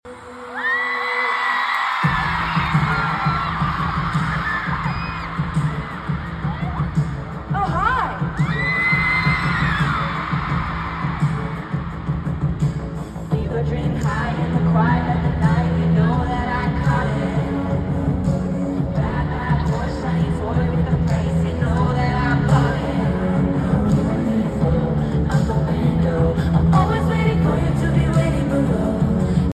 Pittsburgh night 1